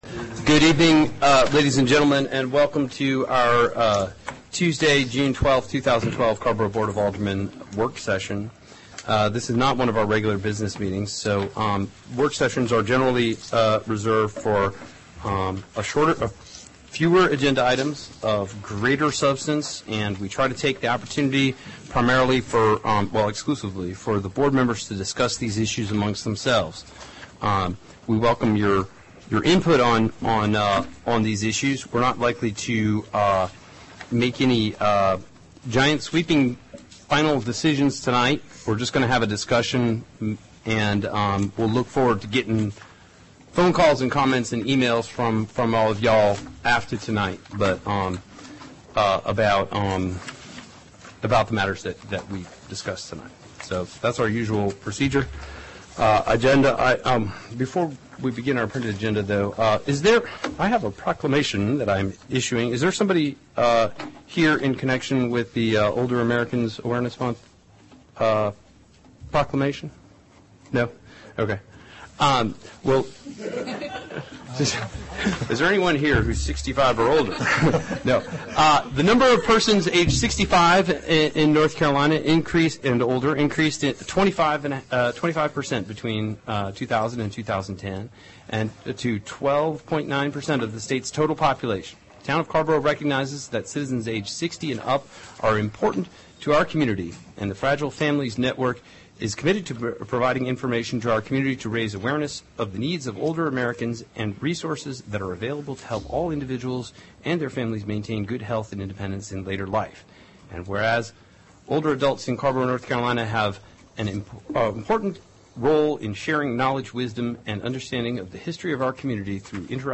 AGENDA CARRBORO BOARD OF ALDERMEN WORKSESSION* Tuesday, June 12, 2012 7:30 P.M., TOWN HALL BOARD ROOM